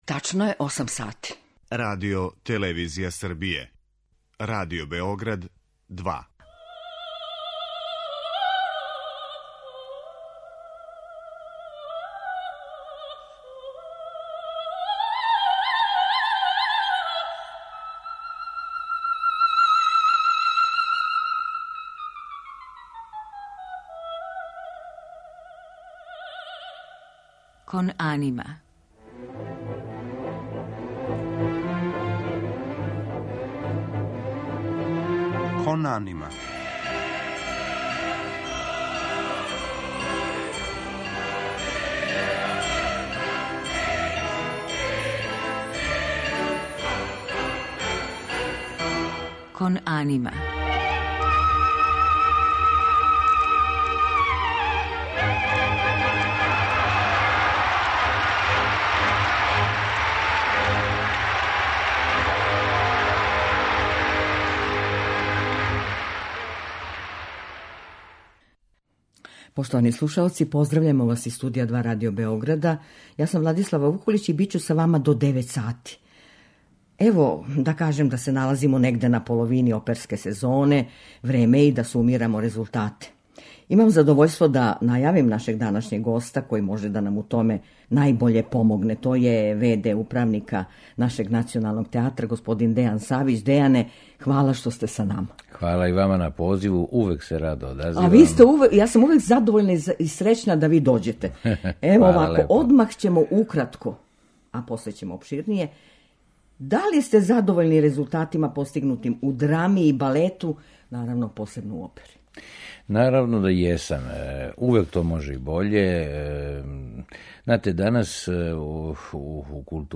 Гост у емисији је в. д. управника Народног позоришта у Београду - диригент Дејан Савић.